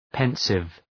{‘pensıv}
pensive.mp3